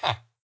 sounds / mob / villager / yes3.ogg